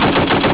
CANNON.WAV